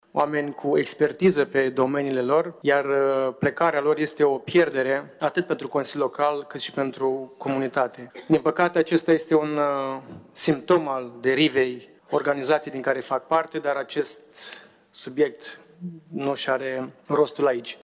Liderul consilierilor USR, Răzvan Timofciuc a recunoscut că organizația din care face parte se află în derivă: Oameni cu expertiză pe domeniile lor, iar plecarea lor este o pierdere atât pentru Consiliul Local cât și pentru comunitate.